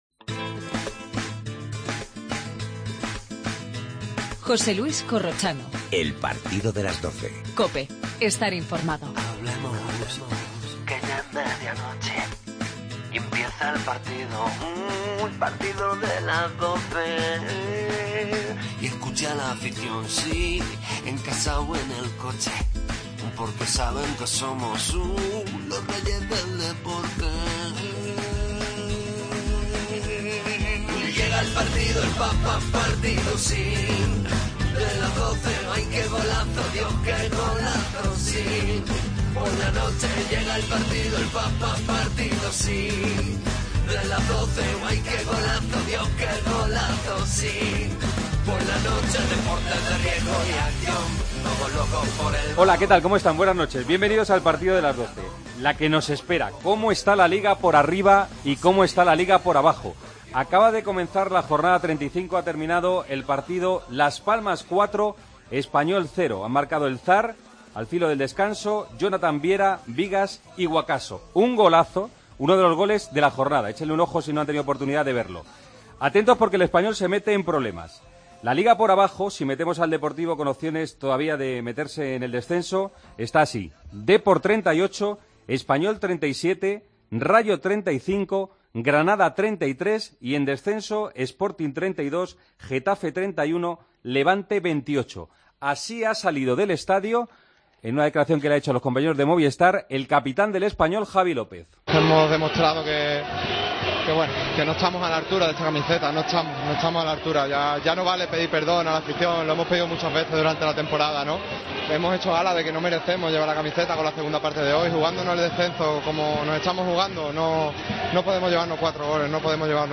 Previa de una nueva jornada con el título de Liga en Juego. Cristiano, baja contra el Rayo. Entrevista a Paco Jémez. Pero la jornada empezó con la victoria de Las Palmas (4-0) frente al Espanyol.